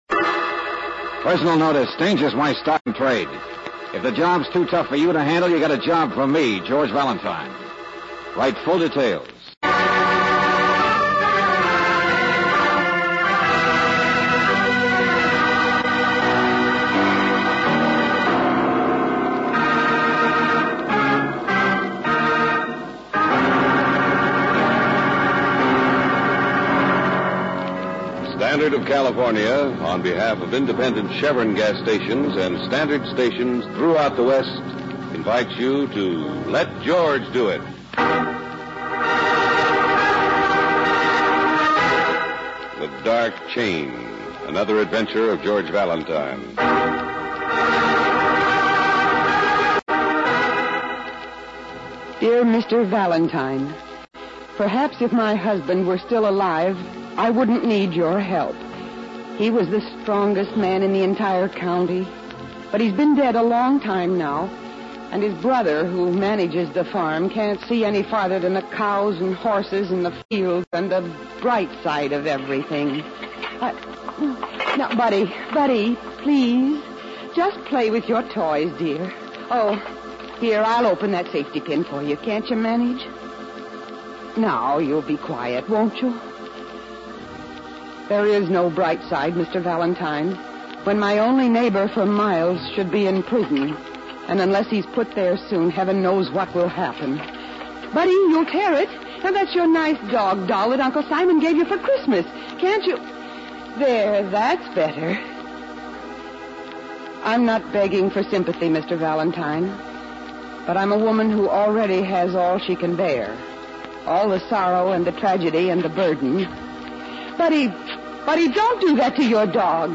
Let George Do It Radio Program